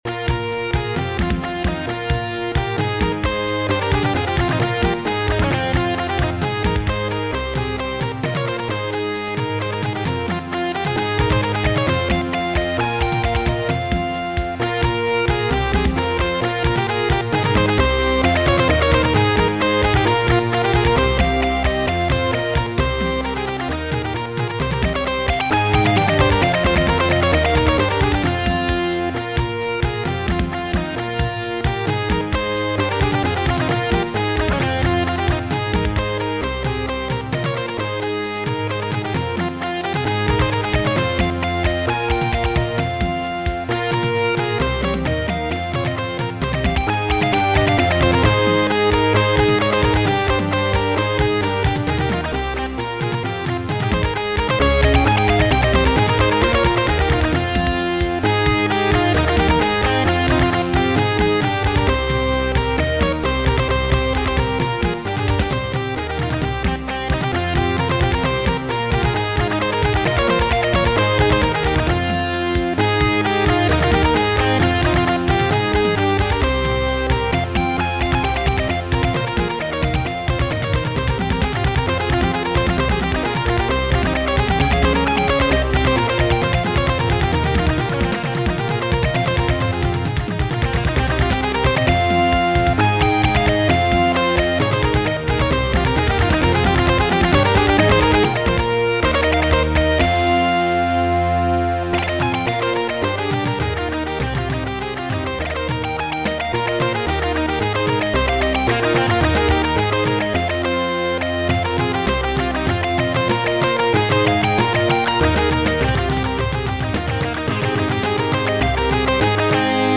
hi, This is a shortened version of folk suite i have recently made. in the music sheets This is for soloist and accompanient. All chords are power chords. Whole suite can be played with two musicians. I have added drums for some parts. Soloist's and accompanients instrument varies.